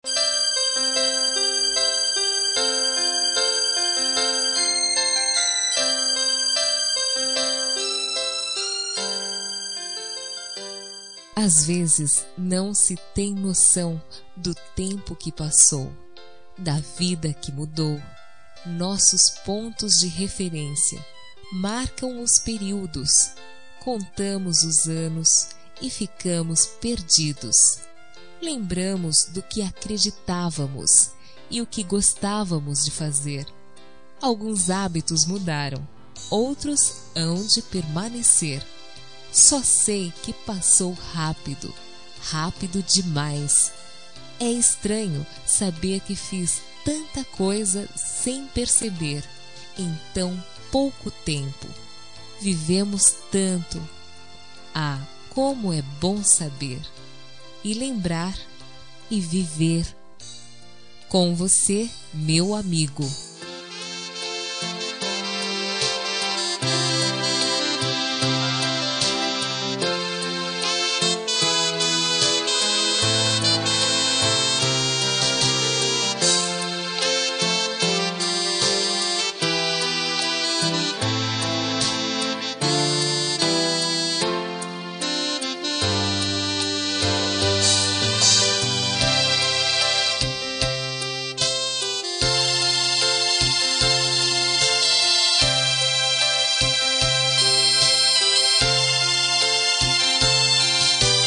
Telemensagem de Amizade – Voz Feminina – Cód: 101
101-amizade-fem.m4a